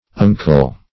unkle - definition of unkle - synonyms, pronunciation, spelling from Free Dictionary Search Result for " unkle" : The Collaborative International Dictionary of English v.0.48: Unkle \Un"kle\, n. See Uncle .